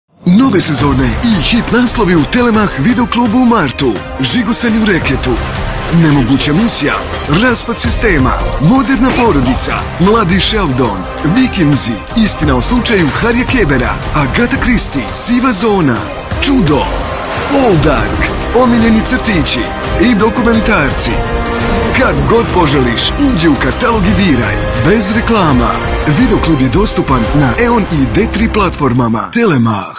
Boşnakca Seslendirme
Erkek Ses